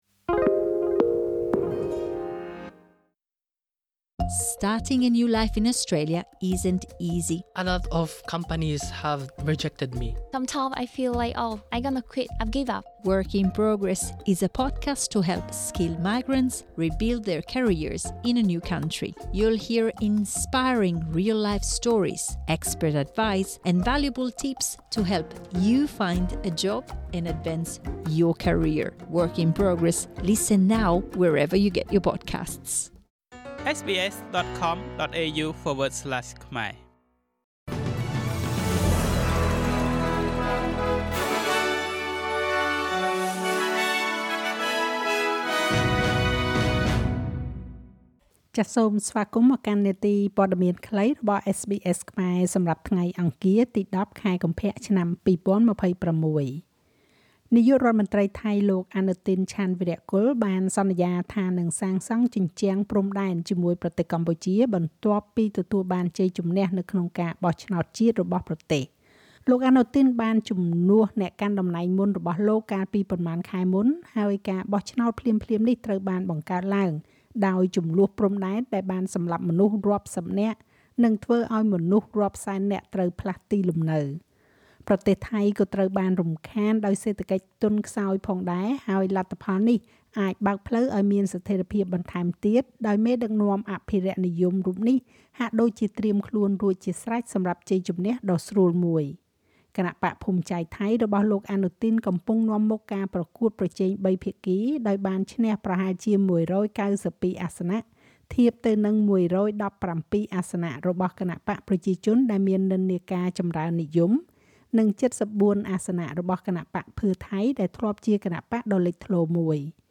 នាទីព័ត៌មានខ្លីរបស់SBSខ្មែរសម្រាប់ថ្ងៃអង្គារ ទី ១០ ខែកុម្ភៈ ឆ្នាំ២០២៦